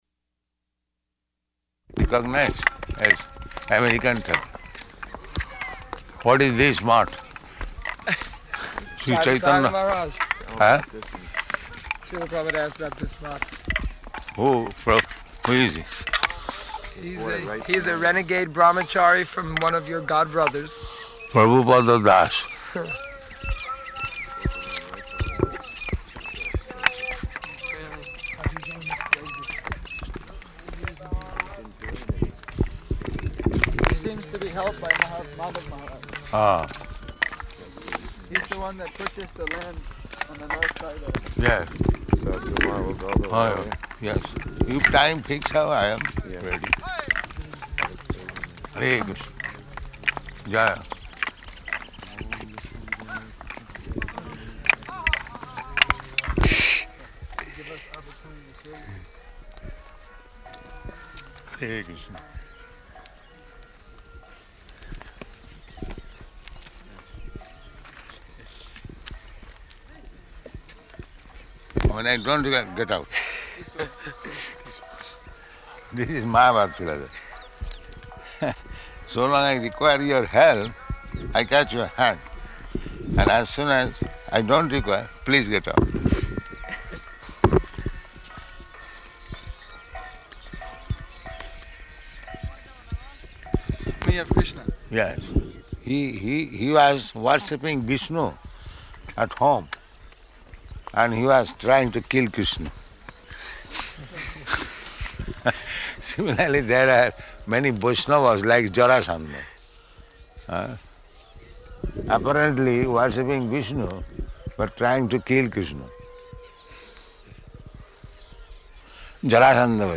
Morning Walk, [partially recorded]
Type: Walk
Location: Māyāpur